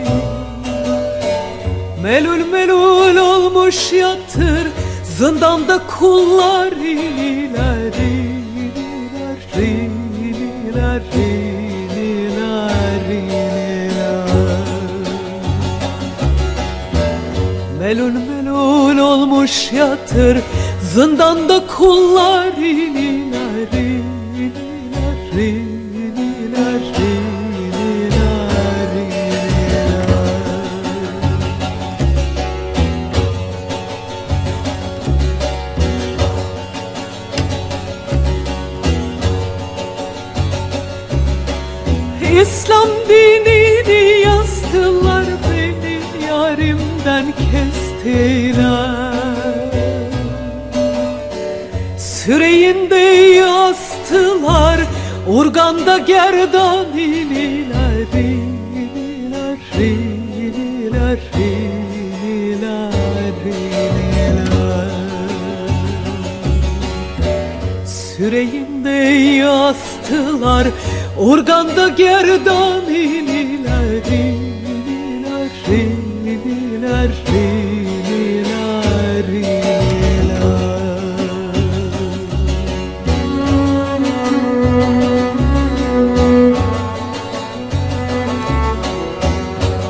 軽やかなアコースティック・サウンドと透明感ある歌声が心地よいフォーク・ナンバー
透明感あるヴォーカルと洗練されたアレンジが魅力のメロウな傑作！